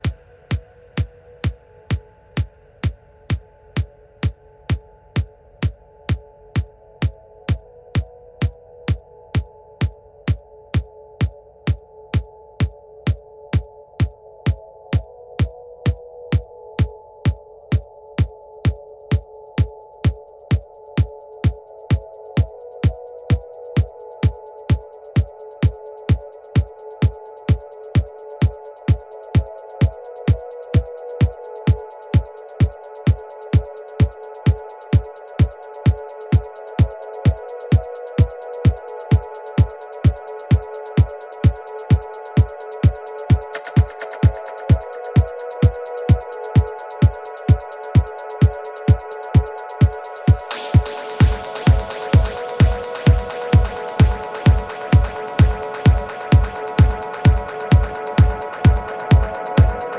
Электронная
Короли транса открывают свежие сочетания мелодий.